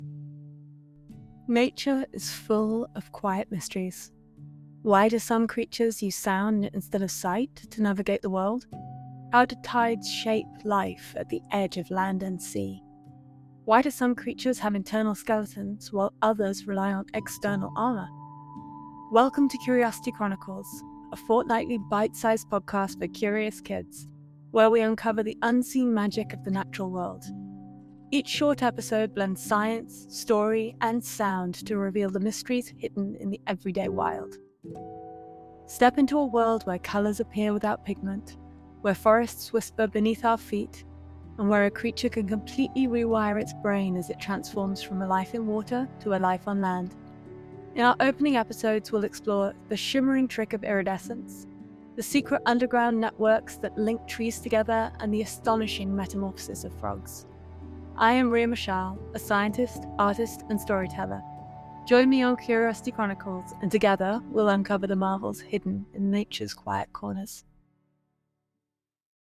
Curiosity Chronicles is a fortnightly, bite-sized podcast for curious kids (and their grown-ups), where science, story, and sound come together to reveal the wonders hidden in the everyday wild.